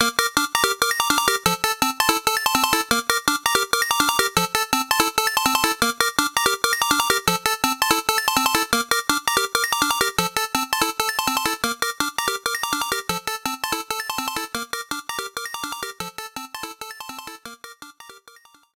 as heard from an attack used by Bowser Memory M
Fair use music sample